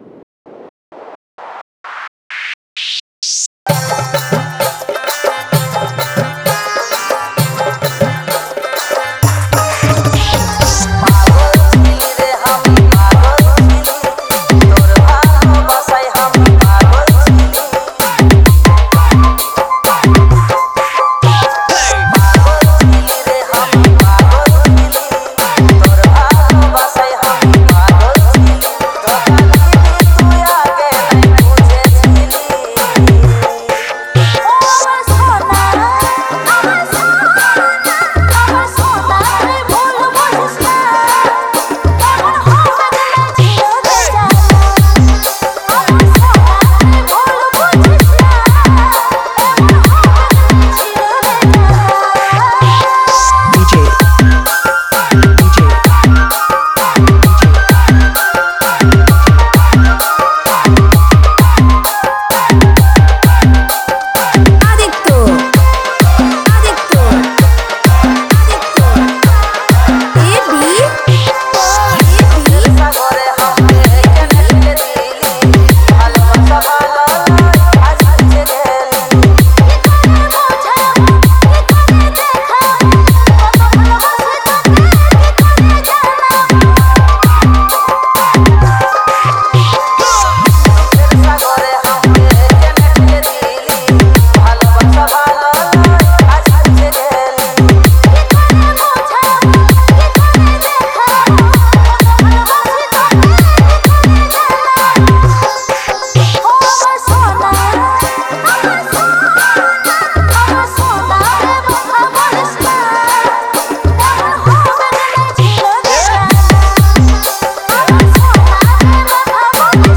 bhojpuri Dj